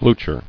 [blu·cher]